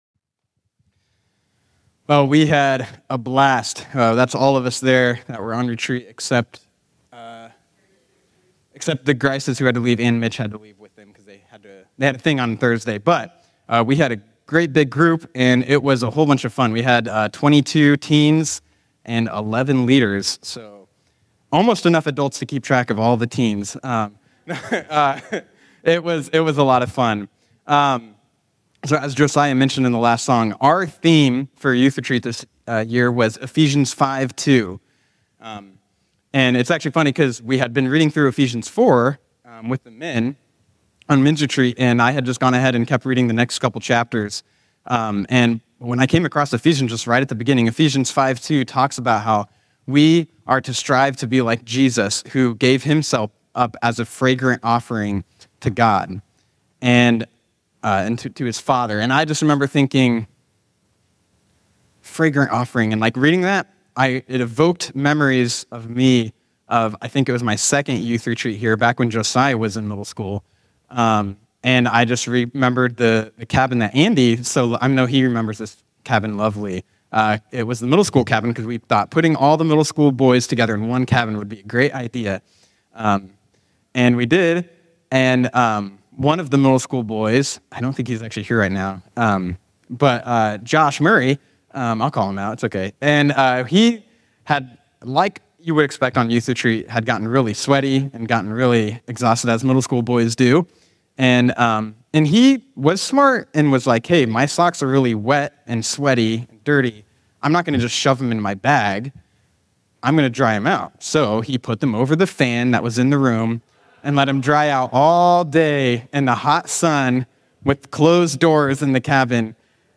Sermons | White Rock Fellowship